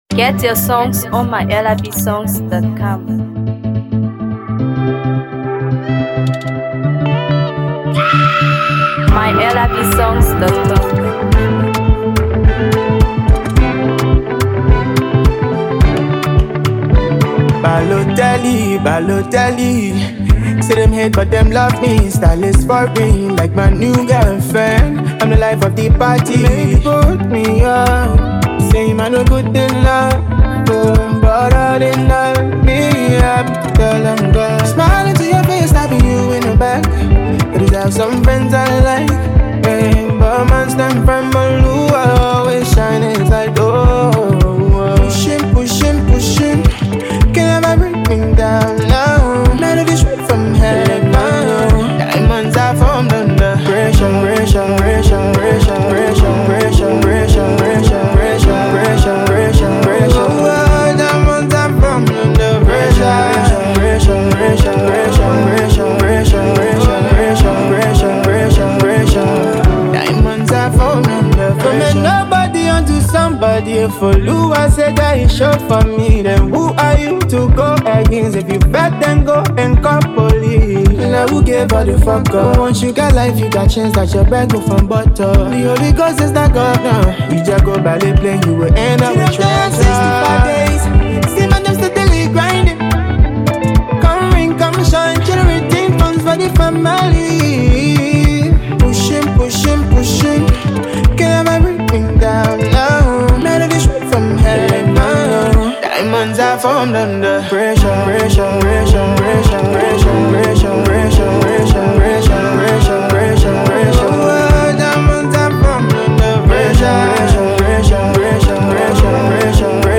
soulful Afrobeat